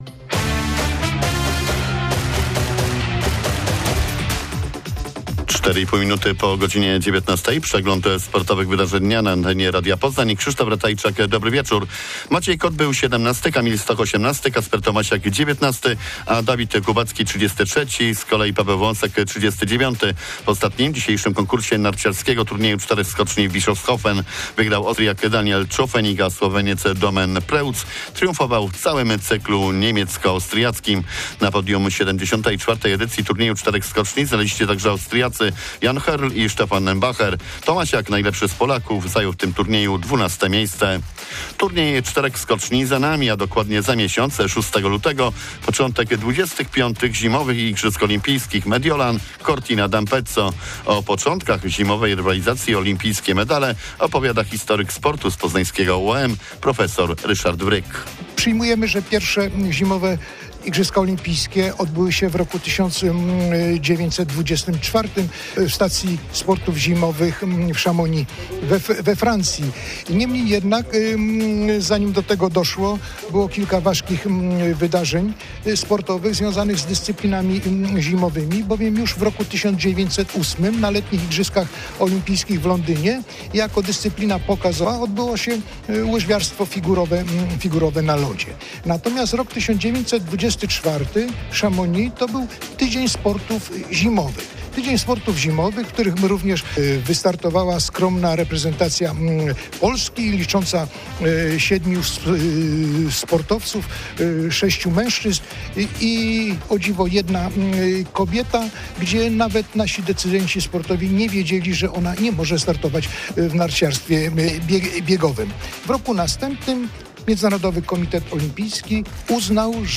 06.01.2026 SERWIS SPORTOWY GODZ. 19:05